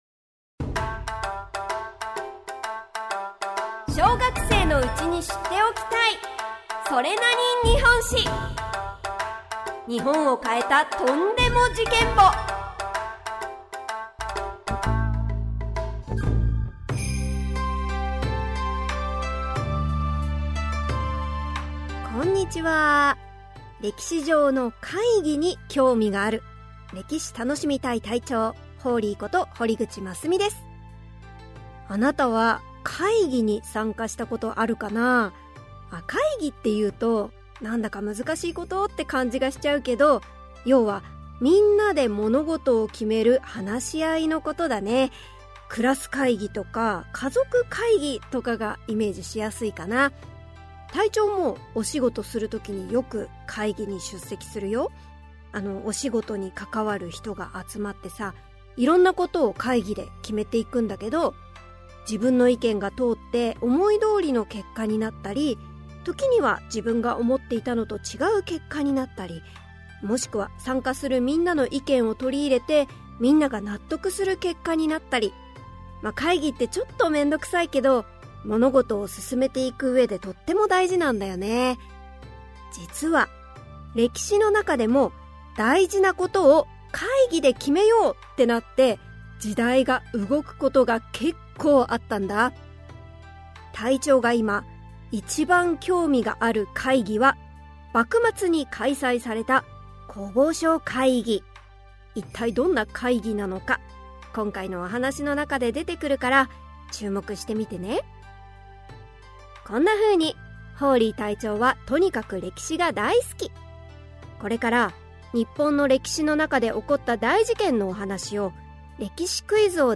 [オーディオブック] それなに？